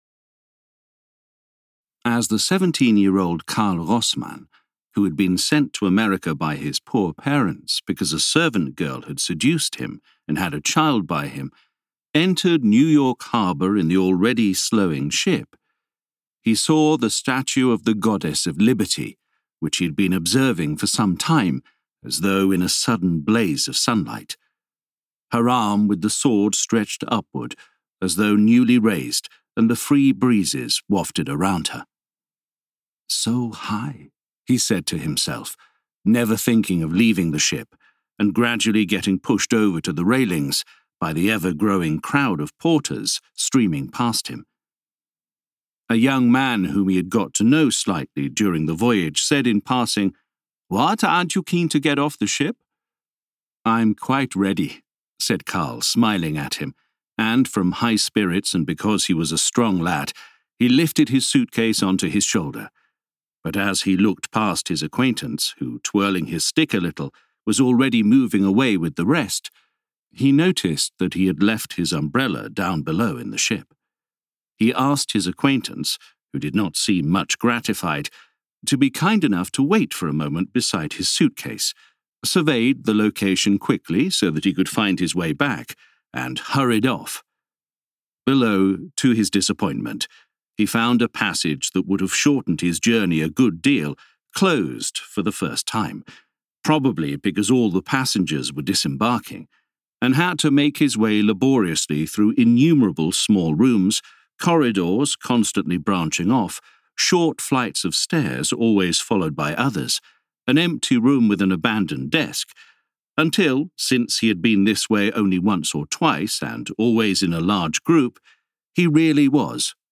The Man Who Disappeared (America) audiokniha
Ukázka z knihy